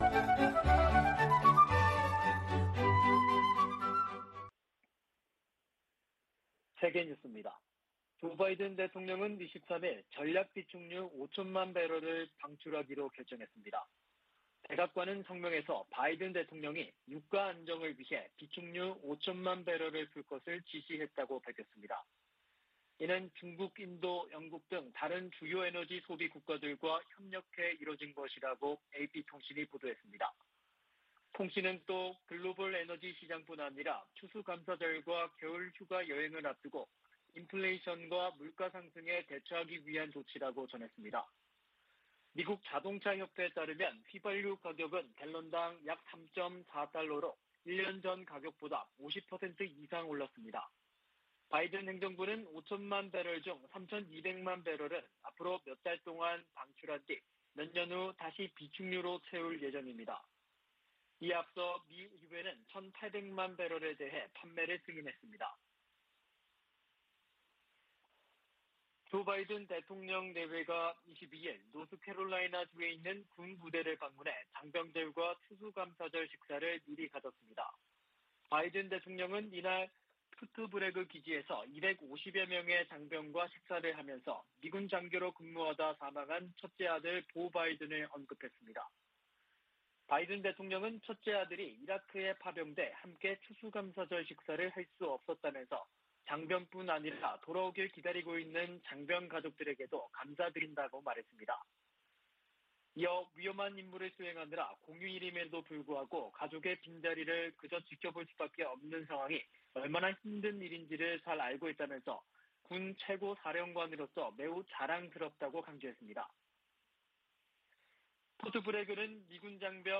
VOA 한국어 아침 뉴스 프로그램 '워싱턴 뉴스 광장' 2021년 11월 24일 방송입니다. 세계 각국이 북한 해외 노동자 송환 보고서를 유엔 안보리에 보고하도록 돼 있지만 보고 비율은 20% 미만에 그치고 있습니다. 미국이 핵 문제와 관련해 이란과 ‘간접 협상’을 재개하지만 북한과의 협상은 여전히 재개 조짐이 없습니다.